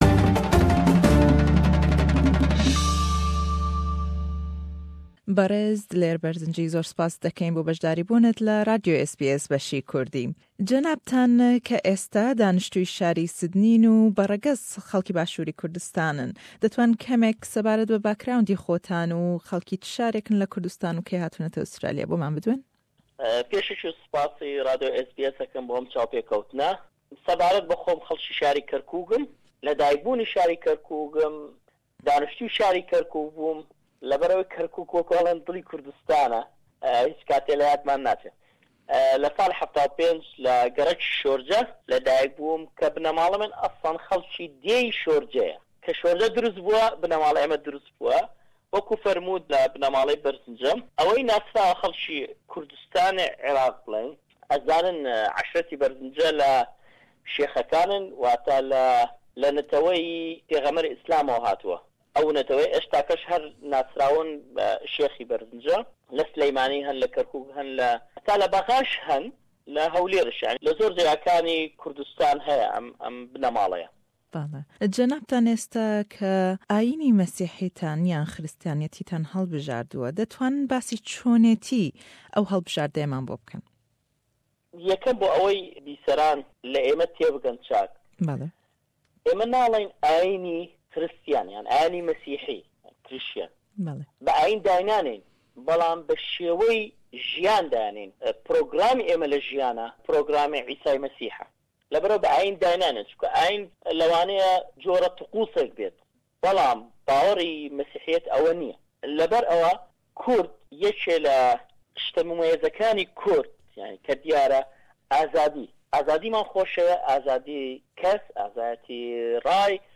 Le em hevpeyvne da e bas hokarekan ew hellbijarteyey xoyman bo dekat.